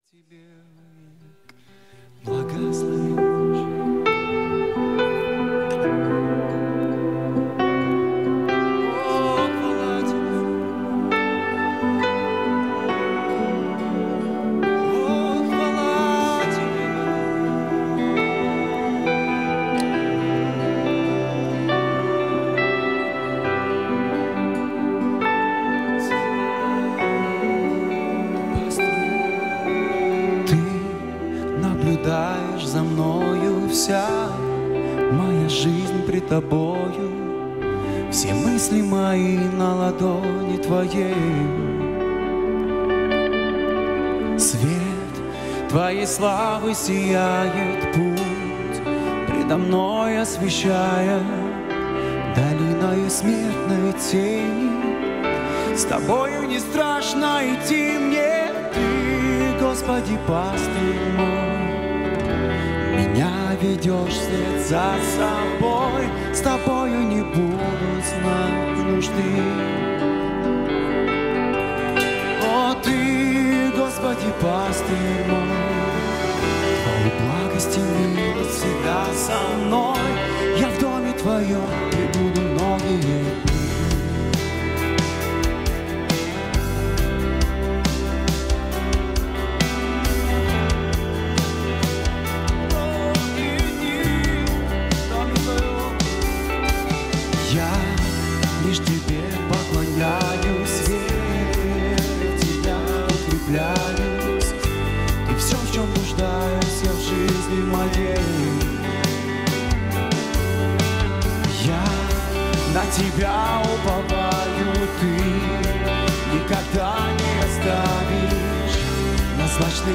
99 просмотров 253 прослушивания 12 скачиваний BPM: 136